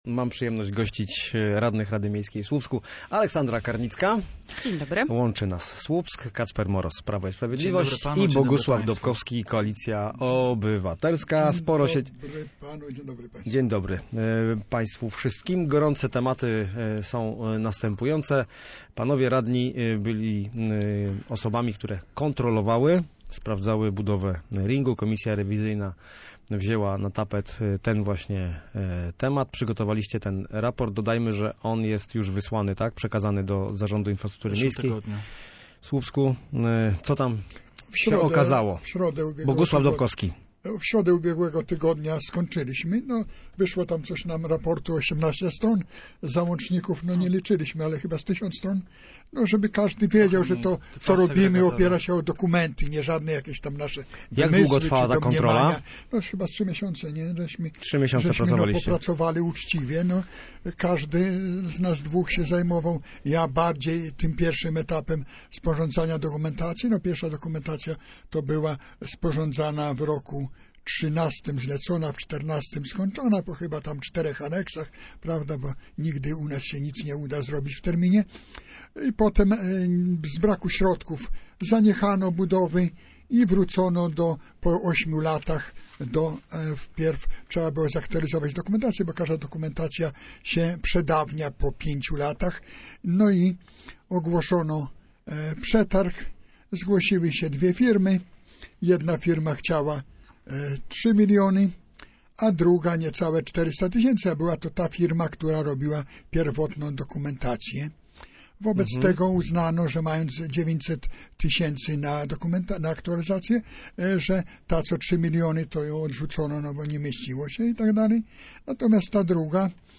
Goście Studia Słupsk rozmawiali również o planie termomodernizacji miasta oraz wysokich dodatkach specjalnych dla kadry zarządzającej słupskim ratuszem.